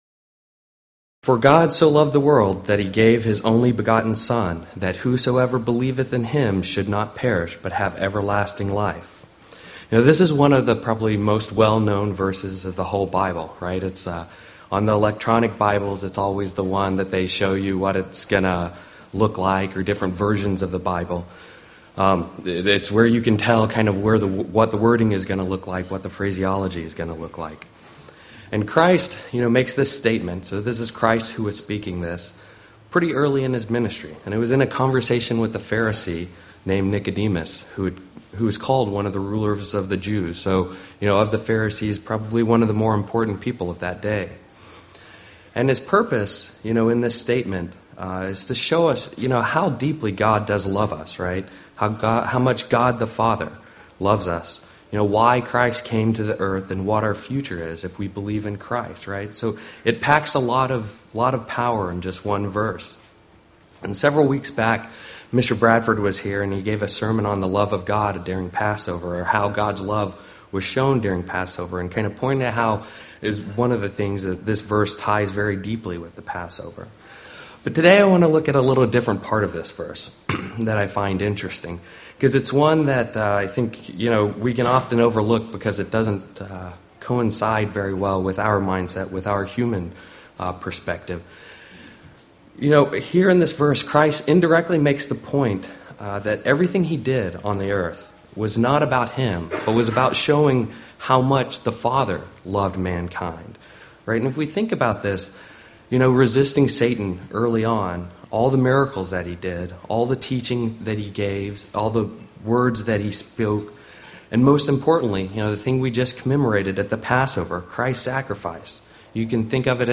Print UCG Sermon Studying the bible?
Given in Seattle, WA